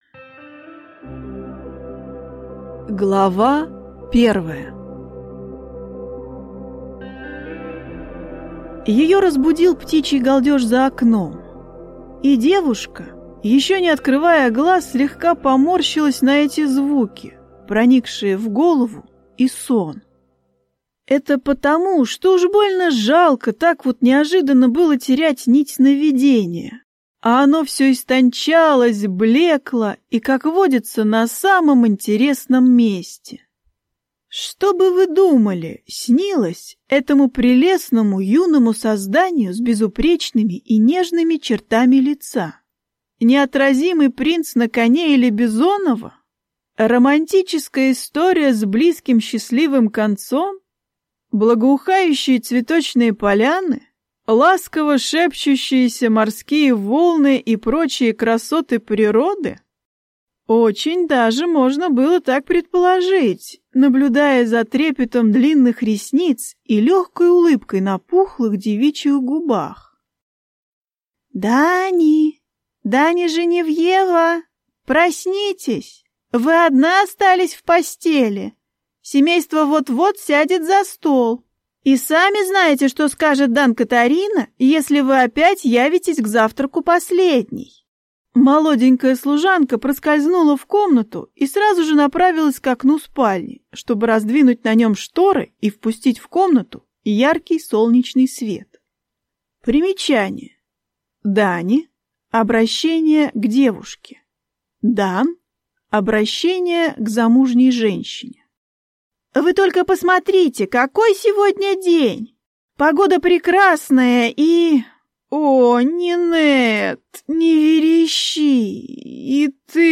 Аудиокнига Предложение, от которого невозможно отказаться | Библиотека аудиокниг